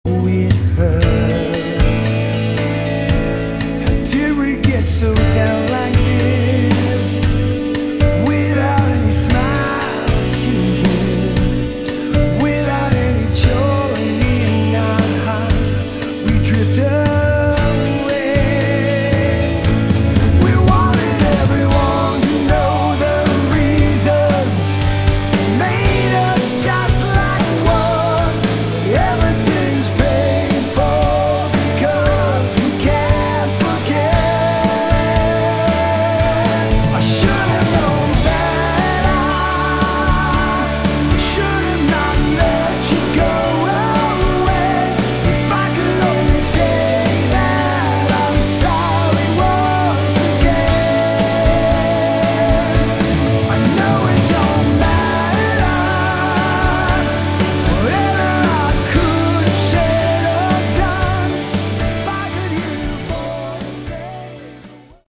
Category: Melodic Rock / AOR